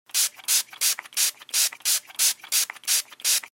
Звук пшык от спрея